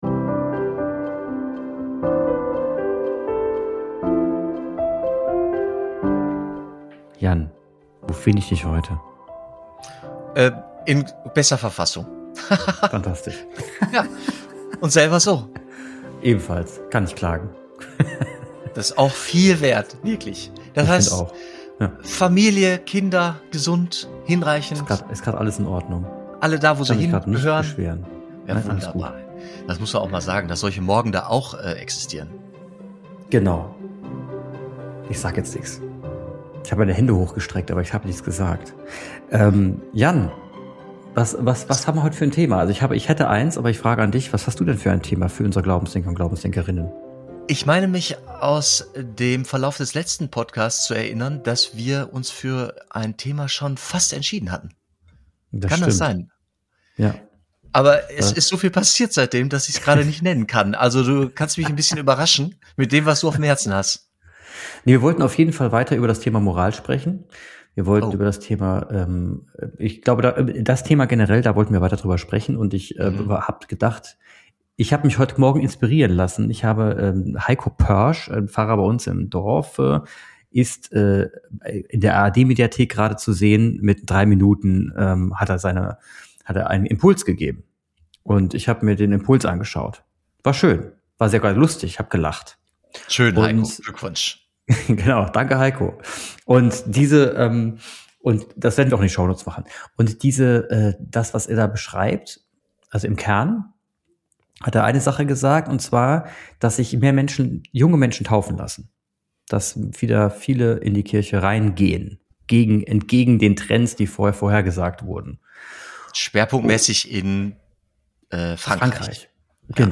Es geht um die Frage, was digitale Formate leisten können (und was nicht), warum gemeinsames Beten Beziehungen verändert und weshalb echte Begegnung durch keinen Bildschirm vollständig zu ersetzen ist. Ein Gespräch über Resonanz, Moral, Segen „per Fernseher“, neue Aufbrüche in der Kirche und die große Chance, Gemeinschaft heute neu zu denken.